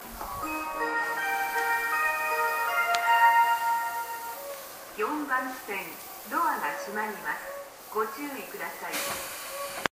スピーカーはNational型が使用されており 音質がとてもいいですね。
発車メロディーフルコーラスです。降りた電車で収録しました。